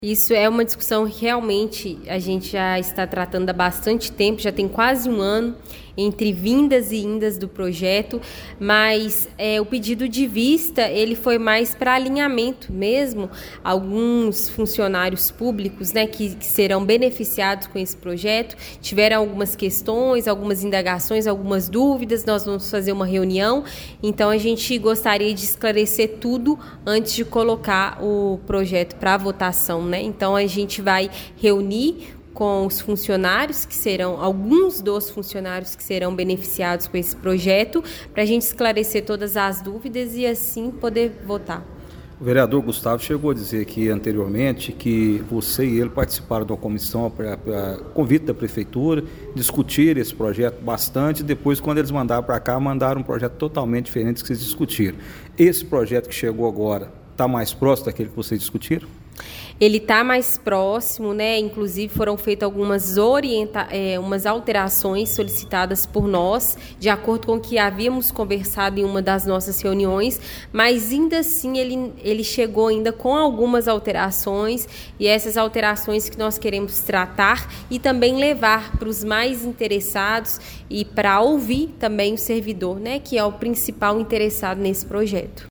Os vereadores participaram da 6ª Reunião Ordinária da Câmara Municipal de Pará de Minas, realizada ontem (24), com pautas voltadas a direitos sociais e à inclusão.